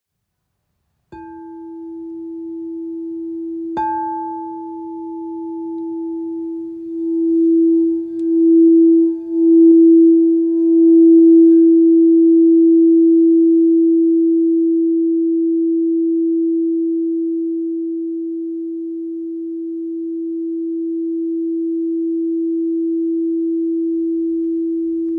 11 inch Crystal Bowl Range A
Our frosted crystal bowls are designed to deliver the best sound quality on the market.
The choice of appropriate diameters for each frequency gives them exceptional resonance stability.
Diameter: 28cm
Note: Mi